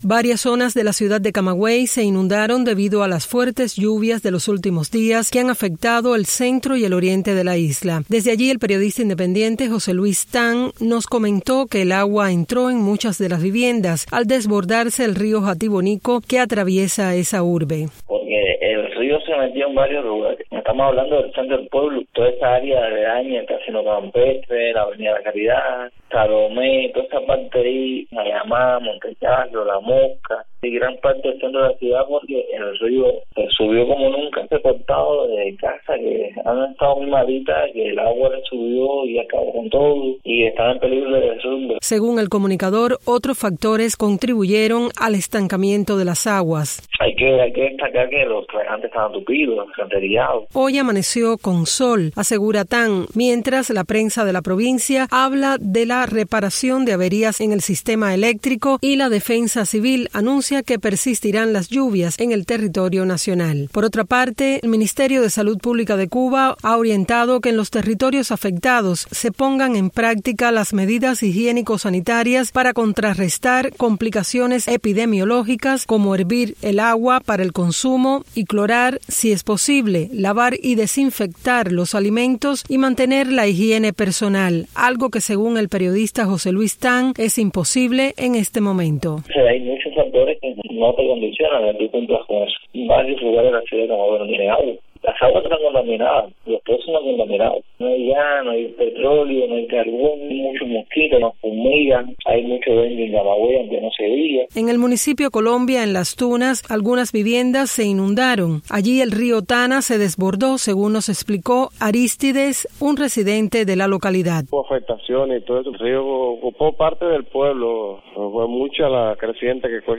Residentes de Camagüey y Las Tunas dijeron este lunes a Radio Martí que no tienen los medios para tomar las medidas sanitarias recomendadas por las autoridades tras las severas inundaciones que afectaron el centro y oriente de Cuba en los últimos días.